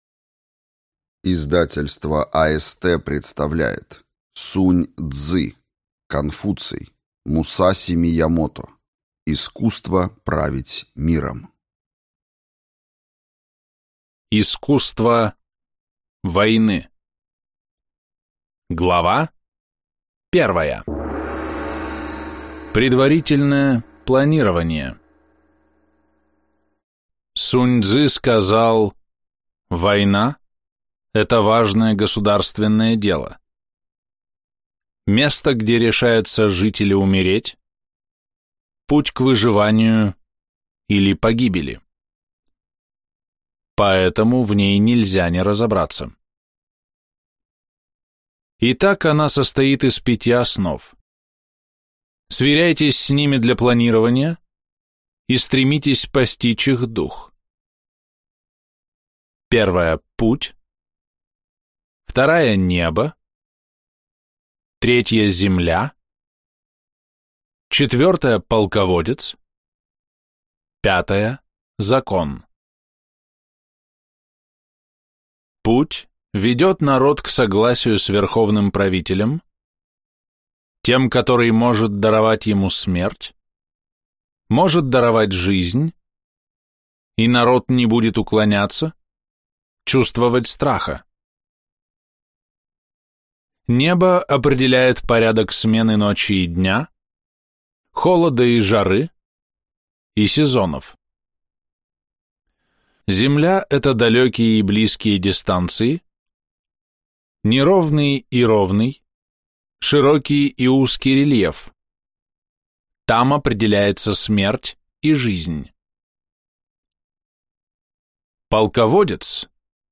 Аудиокнига Искусство править миром | Библиотека аудиокниг